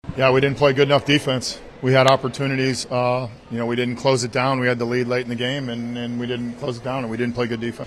Manager Derek Shelton says poor fielding cost the Pirates the game.